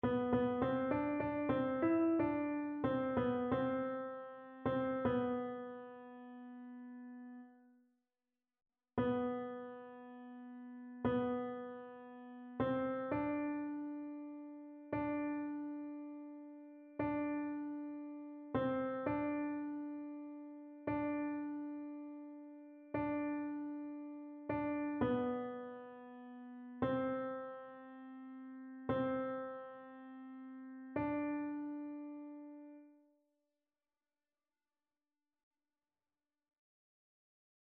Ténor
annee-abc-fetes-et-solennites-saint-joseph-psaume-88-tenor.mp3